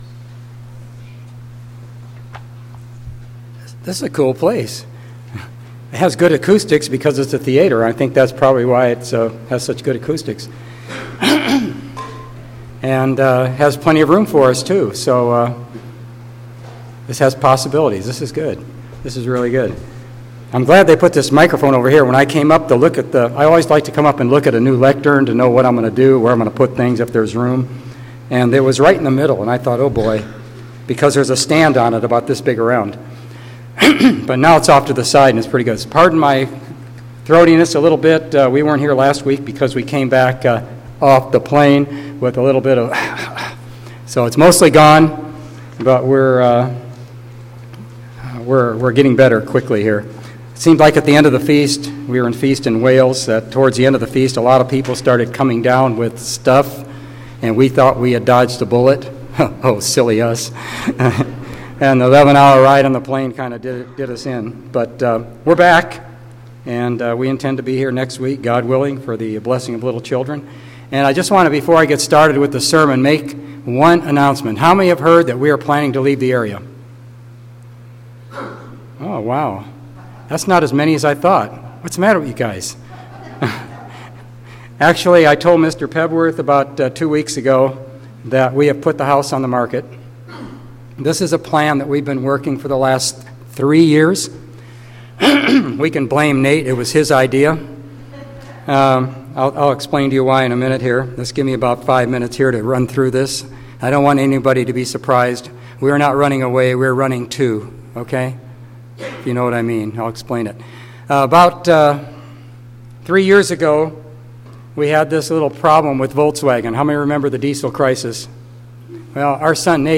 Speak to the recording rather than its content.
Given in Petaluma, CA San Francisco Bay Area, CA